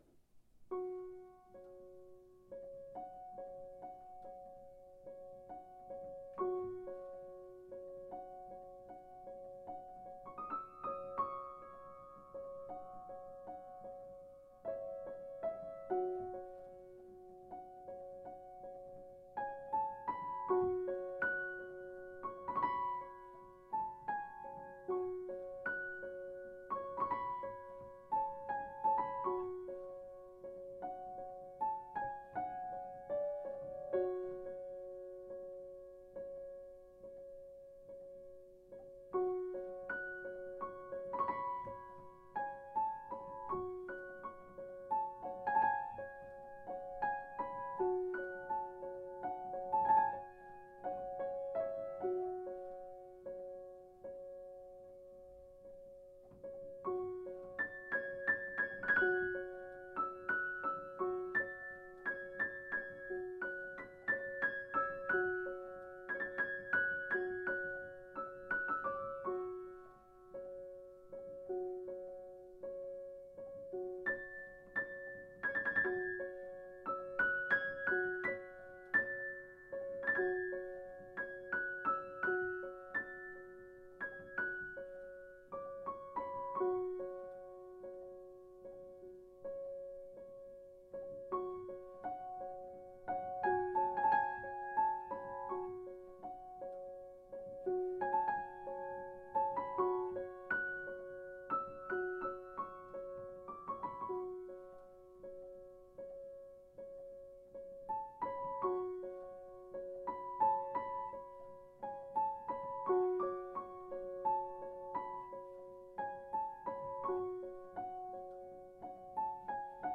I tried to really listen to the sound of the piano more than I had been, and kept my focus reasonably well. I had one magic moment where I heard the overtones from a low Db and brought in the F right above middle C to match them, but I wasn’t crazy about what I played afterwards; it felt cheesy.